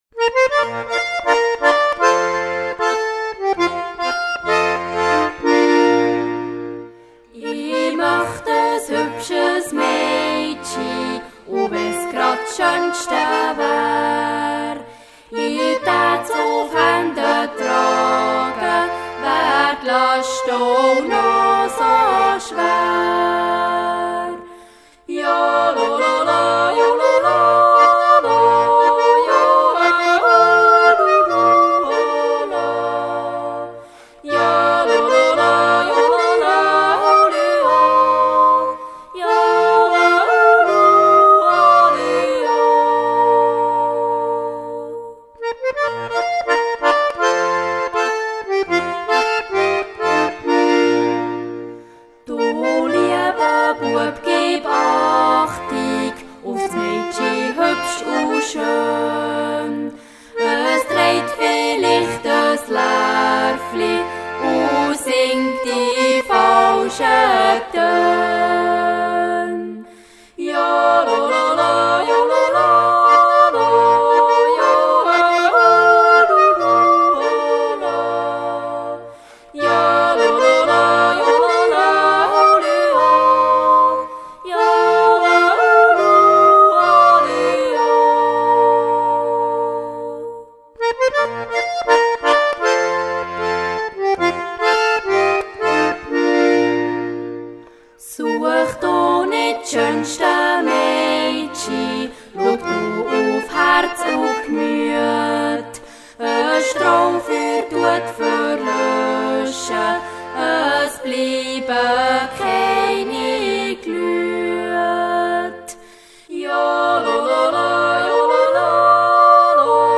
Yodel songs.
sing frugal yodel classics
Swiss diatonic accordeonist
the yodel choir